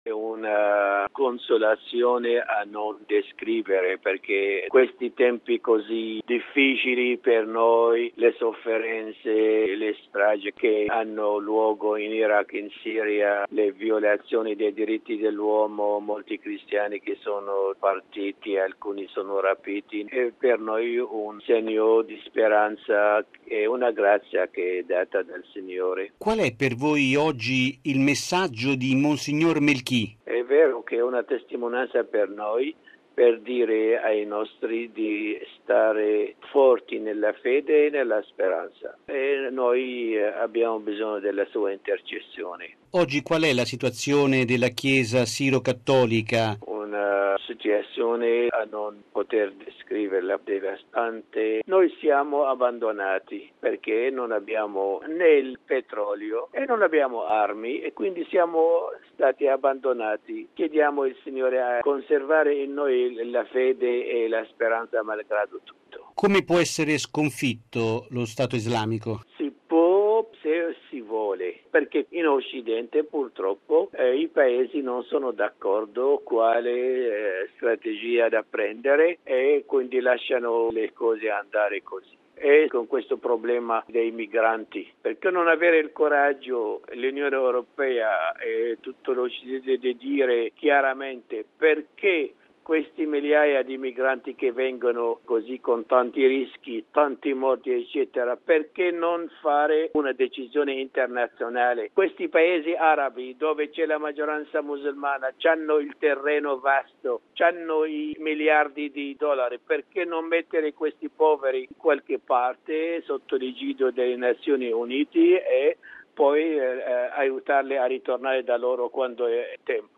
Presiede la solenne liturgia, il patriarca della Chiesa siro-cattolica Ignace Youssif III Younan.